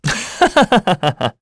Esker-Vox_Happy2_kr.wav